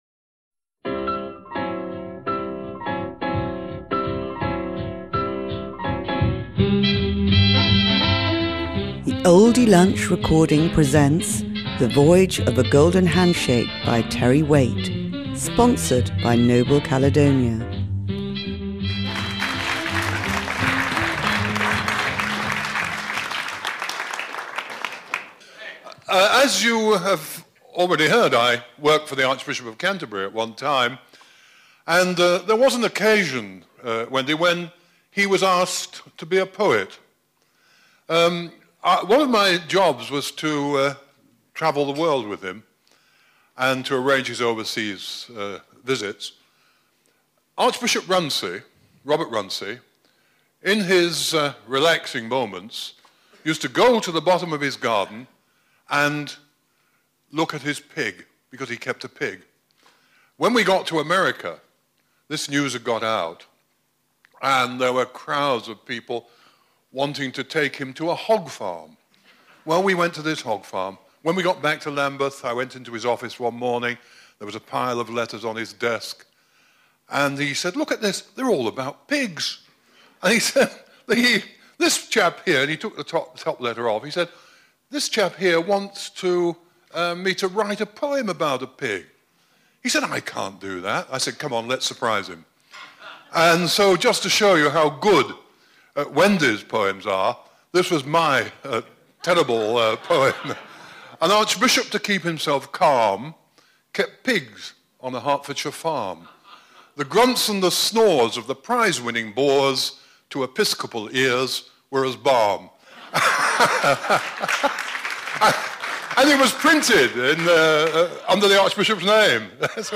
Terry Waites talks about his new book, The Voyage of the Golden Handshake, at the prestigious Oldie literary lunch at Simpson’s in the Strand.